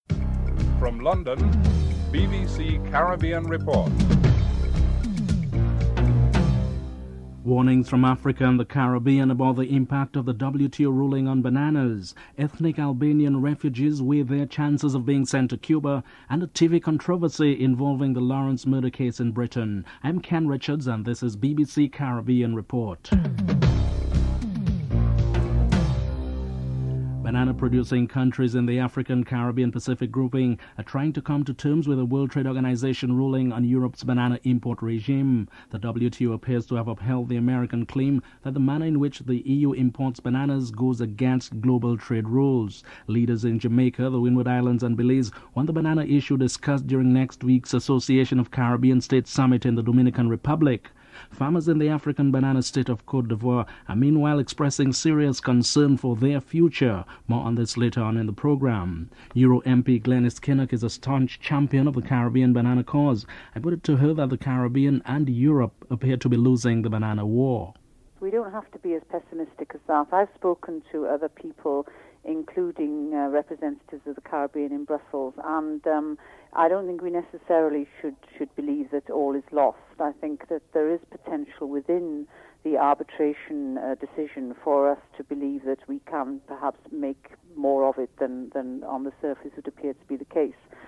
Euro MP Glenys Kinnock discusses the trade war and future arbitration. Politicians and farmers in the Windward Islands and Dominica express disappointment and warn of serious social implications.